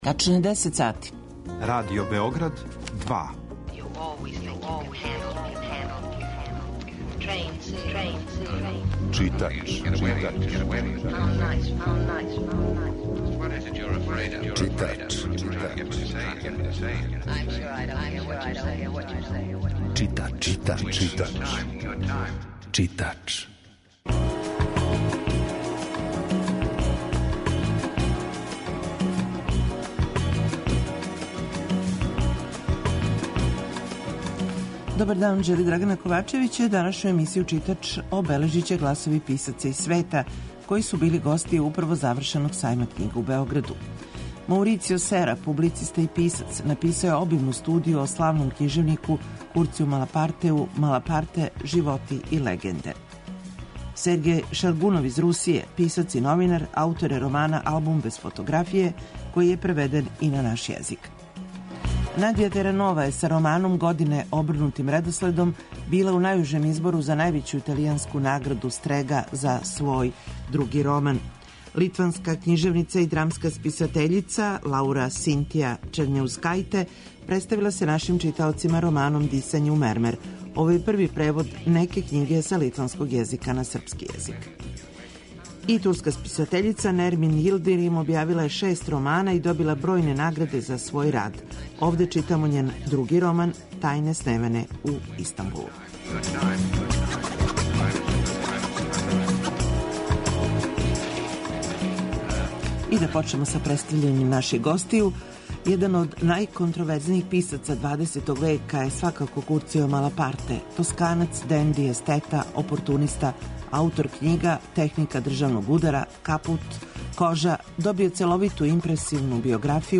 Данашњу емисију 'Читач' обележиће гласови писаца из света који су били гости управо завршеног Сајма књига у Београду.